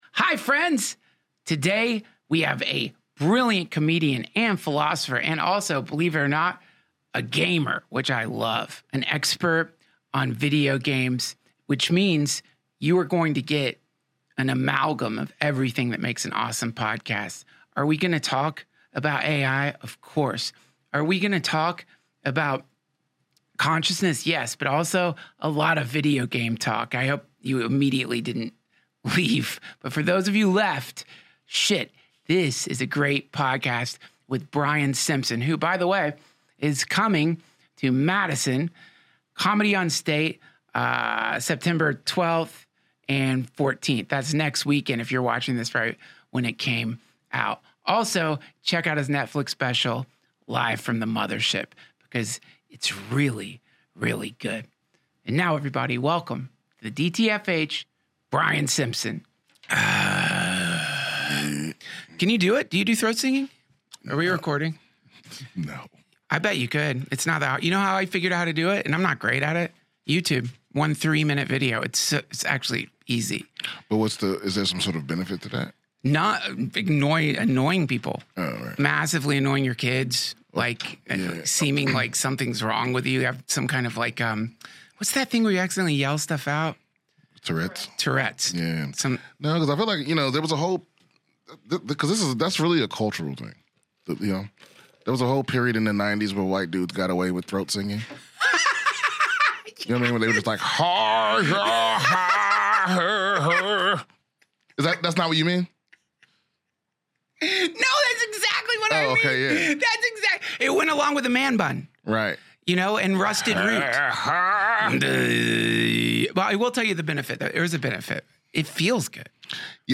Brian Simpson, brilliant comedian, philosopher, and gamer, joins the DTFH!